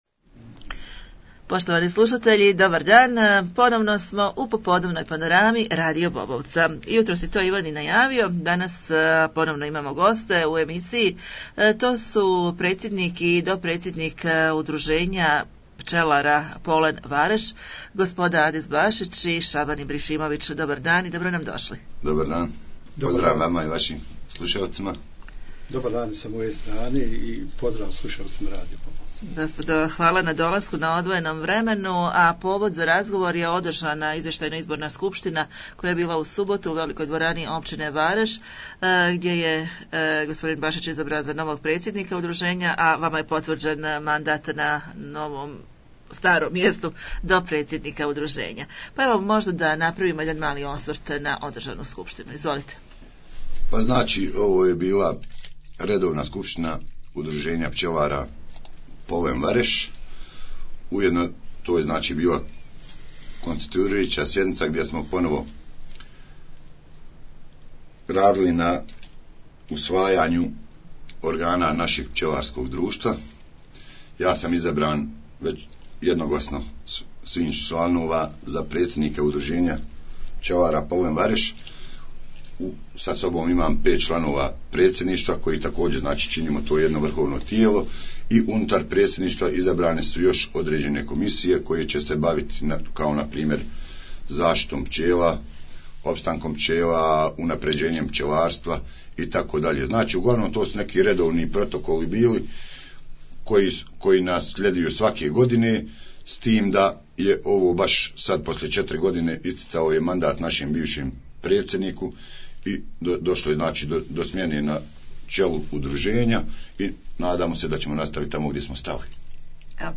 U studiju smo razgovarali